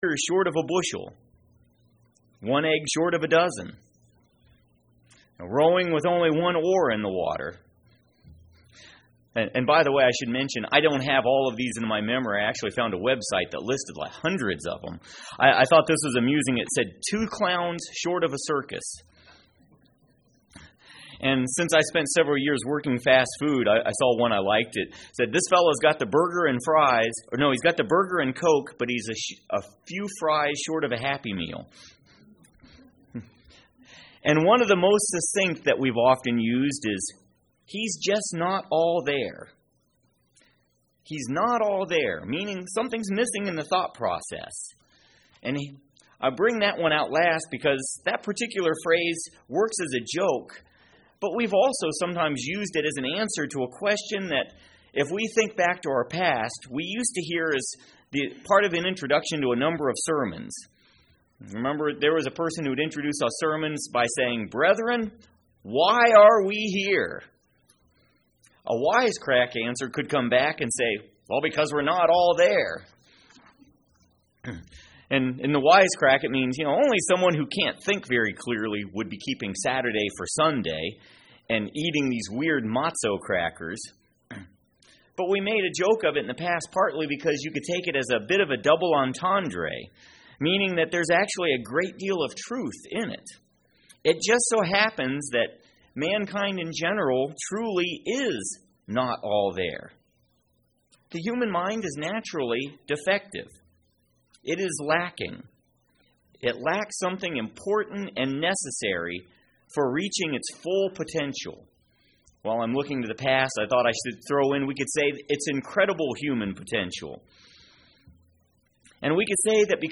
Listen to this sermon to determine just how we can gain the things we are lacking to become a complete person,,spiritually....